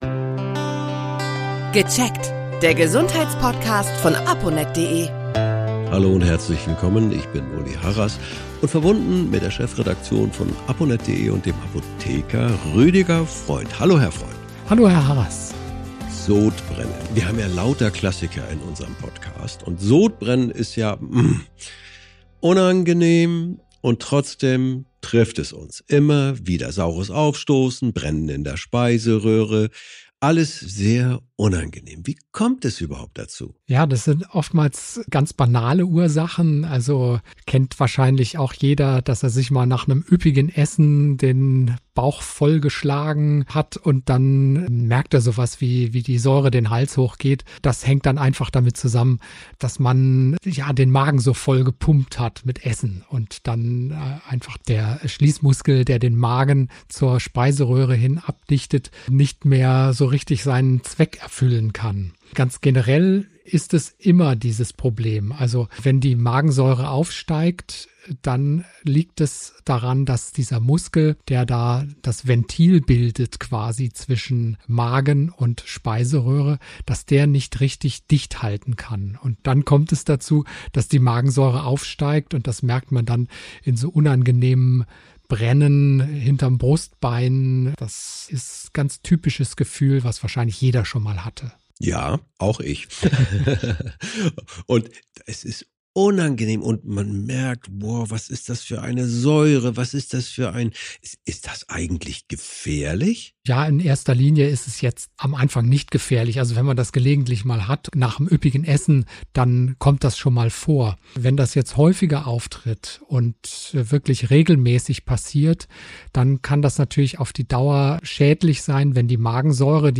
Apotheker in diesem Podcast.